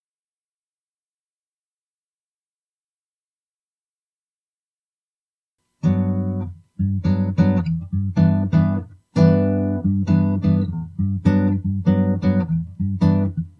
these are MP3s of silence then a short guitar track…I dont know how to send you a picture of the wave form...if you could explain that to me it would be helpful..basically there is hum on my recordings that Im almost positive are internal…I turn off everything in the room when recording including the cooling pad under the computer
the second example is with one time noise reduction set at 18 9 1 which is generally more reduction than I usually apply…its more or less acceptable on my end however Im only hearing it thru somewhat inexpensive headphones and/or speakers…when I post MP3s online I can usually hear some background hum…its not unacceptable but Id like to get rid of as much of it as possible